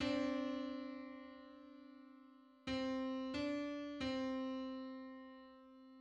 File:Five-hundred-sixty-seventh harmonic on C.mid - Wikimedia Commons
Just: 567/512 = 176.65 cents.
Public domain Public domain false false This media depicts a musical interval outside of a specific musical context.
Five-hundred-sixty-seventh_harmonic_on_C.mid.mp3